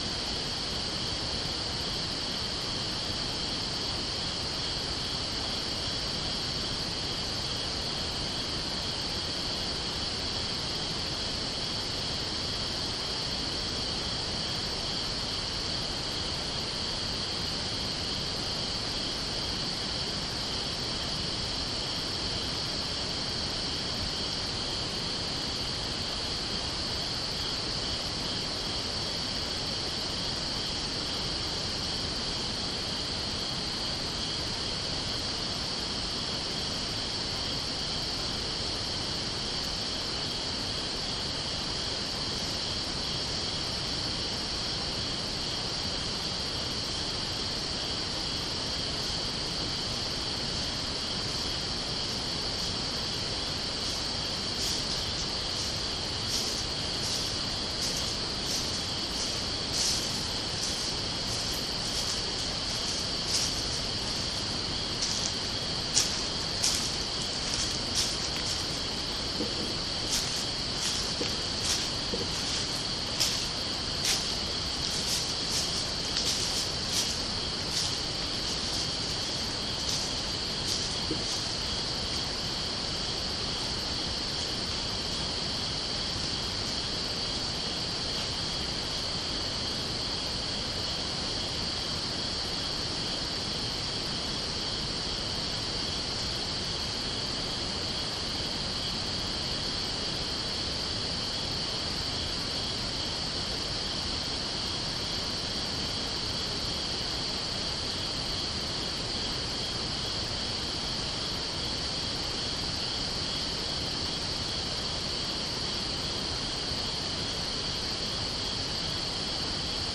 walking_by_the_truck_7_hours_17_minutes.mp3